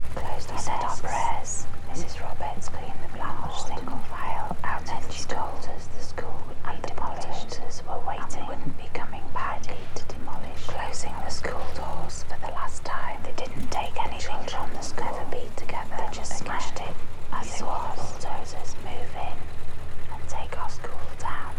With the following track said to train the brain to produce Gamma Waves (above 40Hz) at 50Hz.
multi-track-we-said-our-prayers-with-binaural-beats.wav